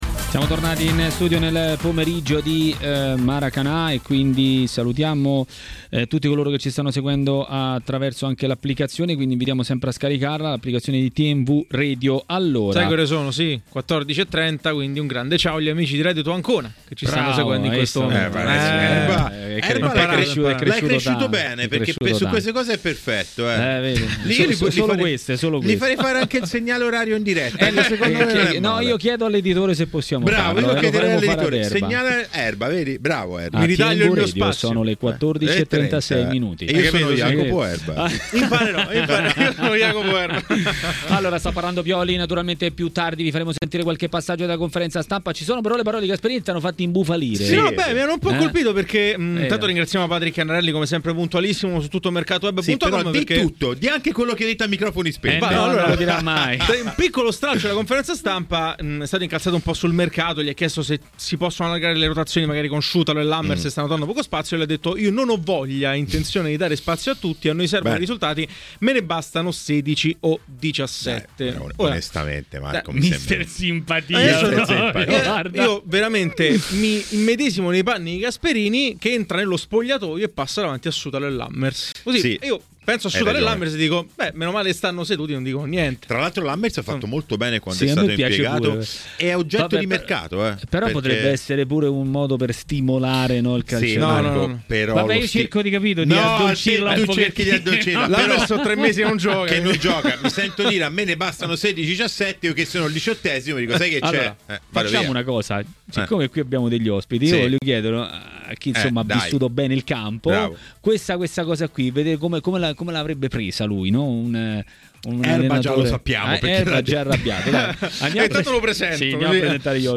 L'ex calciatore e tecnico Bruno Giordano a TMW Radio, durante Maracanà, ha parlato dei temi del giorno.